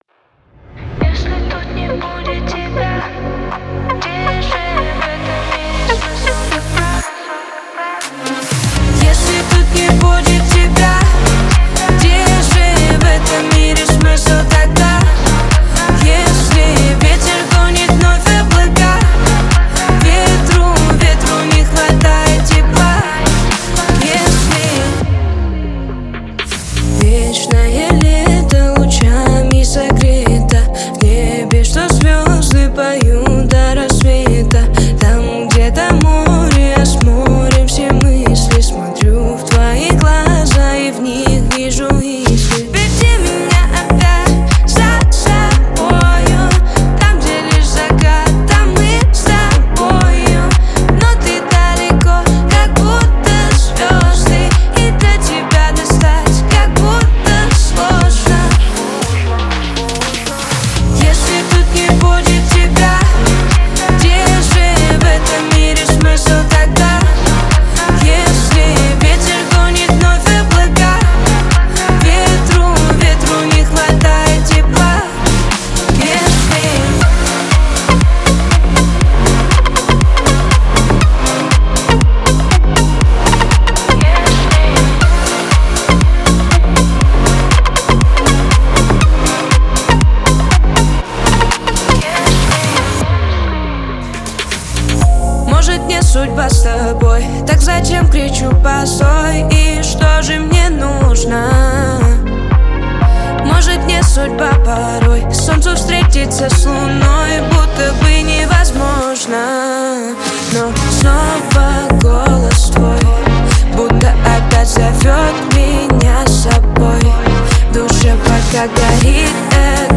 танцевальные песни